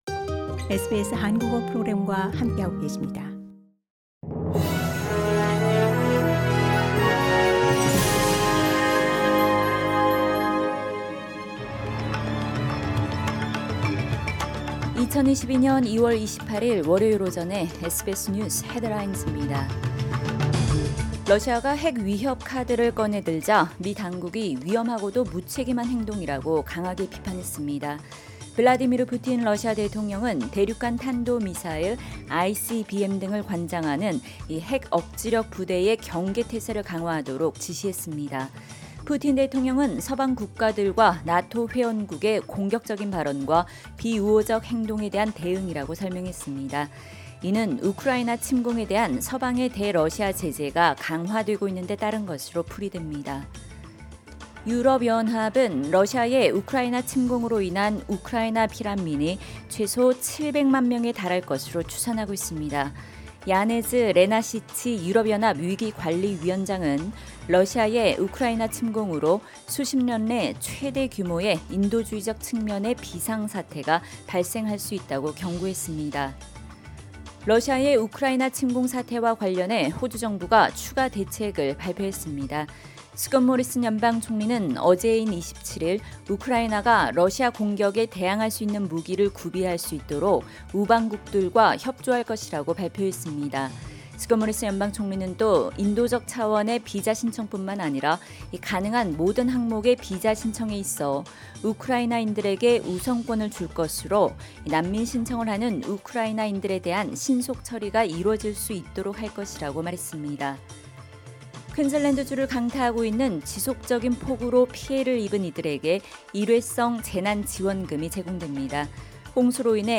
2022년 2월 28일 월요일 오전 SBS 뉴스 헤드라인즈입니다.